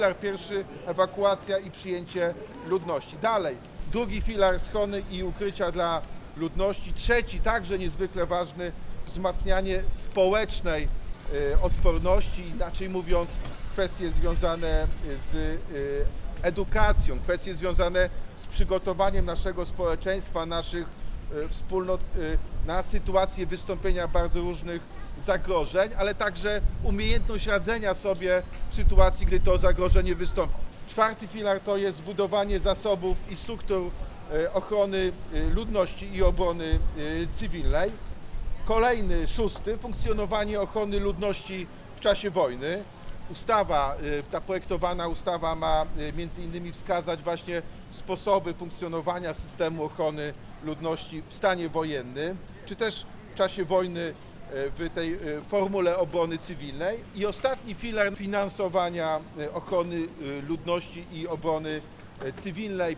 Taka ustawa może poprawić bezpieczeństwo w naszej Ojczyźnie i naszych miastach – Ustawa obejmuje 7 filarów, które mają wzmocnić ochronę ludności i obronę cywilną w razie zagrożenia wojną – mówił Jacek Sutryk, prezydent Wrocławia.